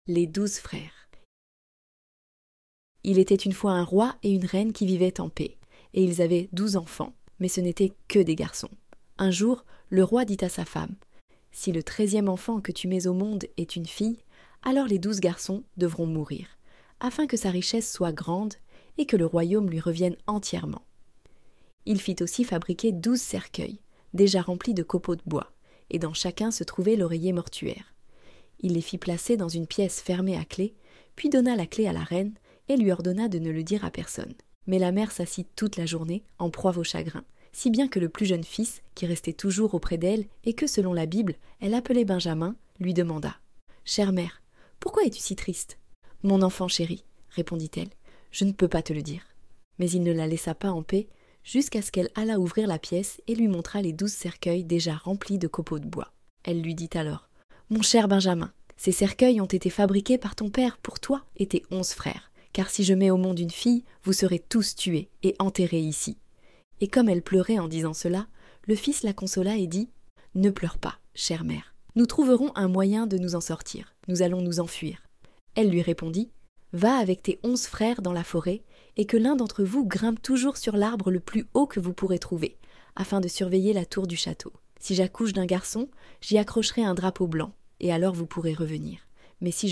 Les-douzes-freres-Conte-de-Grimm-9-Extrait-Audio-livre-Contesdefees.com_.mp3